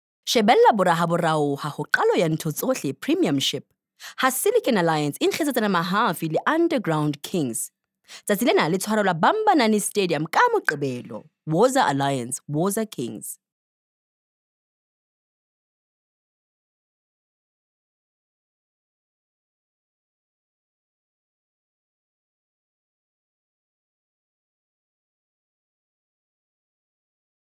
confident, conversational, Formal, friendly, informative, sharp, youthful
Hard Sell.